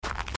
gravel_walking.mp3